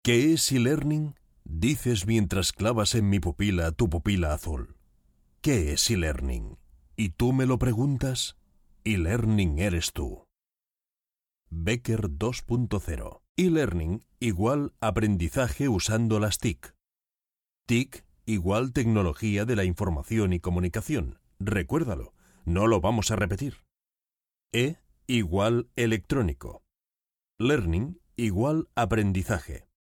Profi Sprecher spanisch. 25 Jahre Sprecher-Erfahrung in spanisch, Castillianisch und Katalan.
kastilisch
Sprechprobe: eLearning (Muttersprache):
spanish voice over Talent with more than 25 years of experience in radio and television.